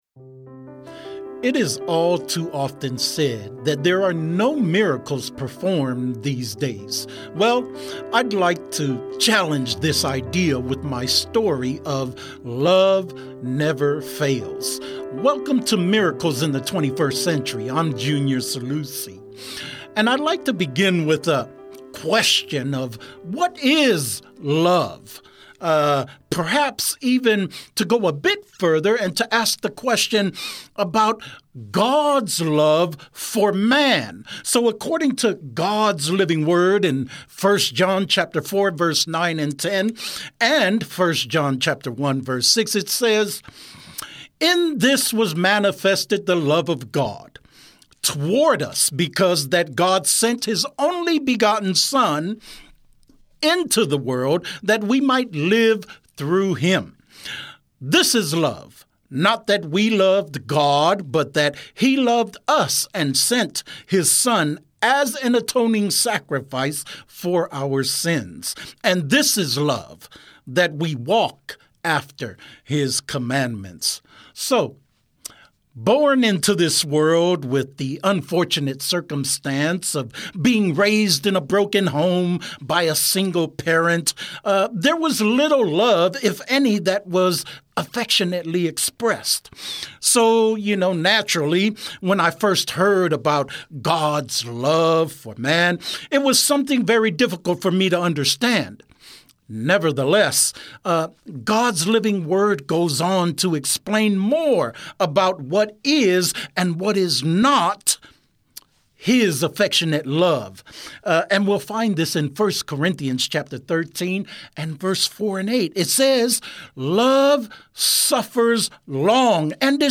Miracles? In the 21st Century? Yes, listen to people share real life miracle stories, not only from recent times but also amazing stories from their past.